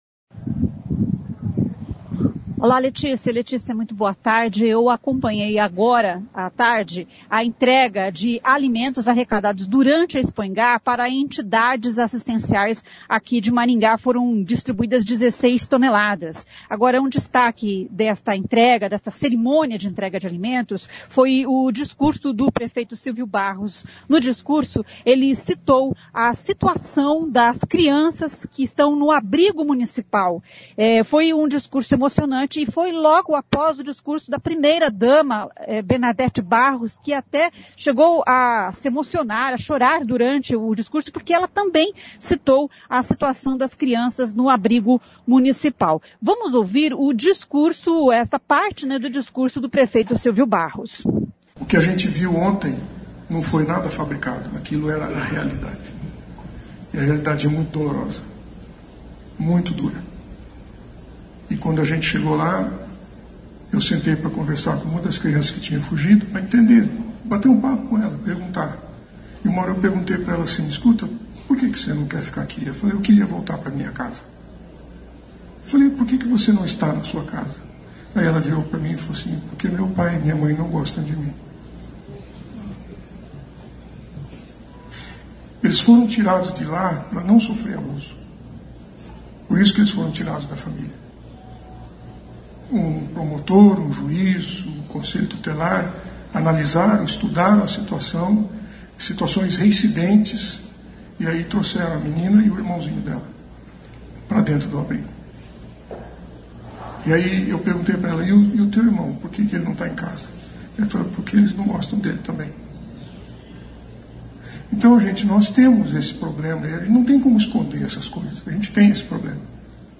De vidros e portas quebrados a acesso ao telhado. O prefeito Silvio Barros citou a situação do abrigo municipal durante a entrega de alimentos arrecadados na Expoingá.